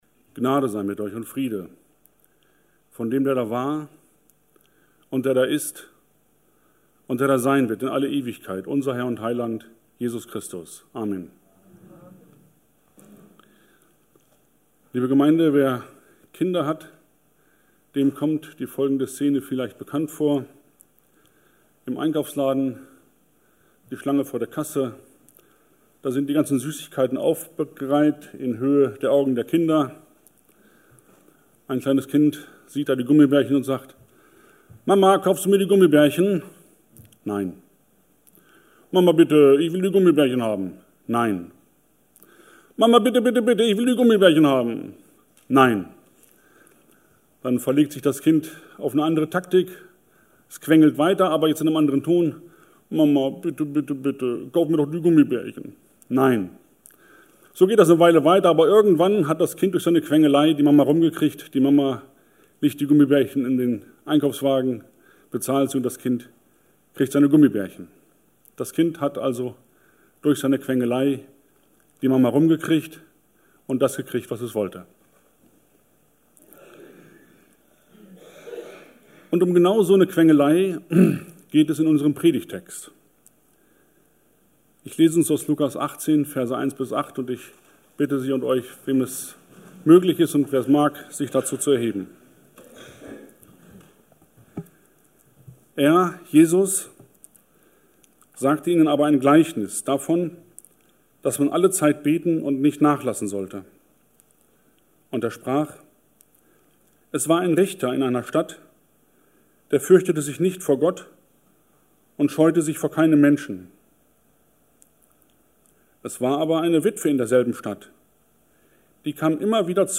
Passage: Lukas 18,1-8 Dienstart: Gottesdienst « Es ist schon mitten unter uns Immer wieder